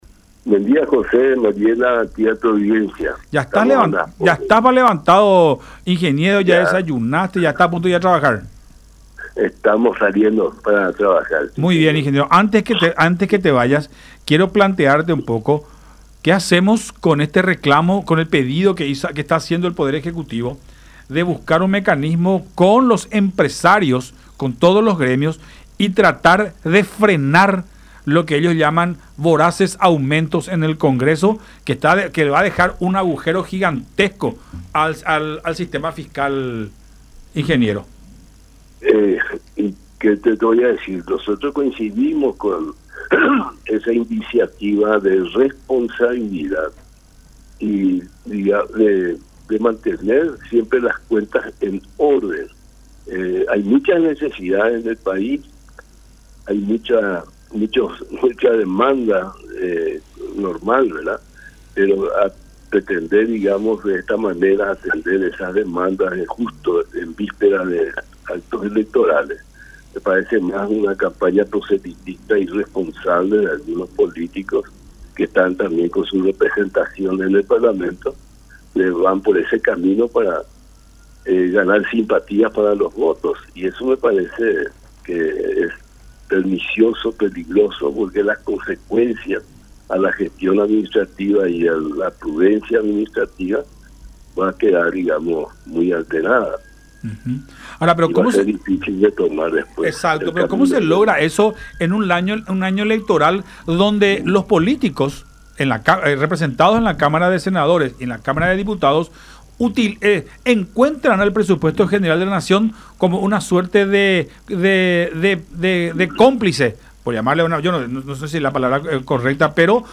en charla con Nuestra Mañana por Unión TV y radio La Unión